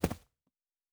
Footstep Carpet Running 1_05.wav